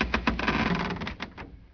wood_creak1.ogg